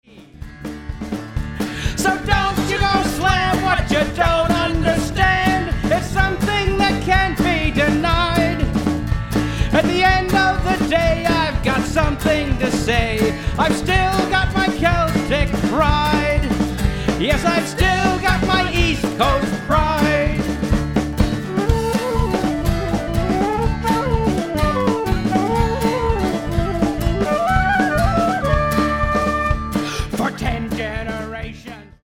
- barn burner Celtic Rock opener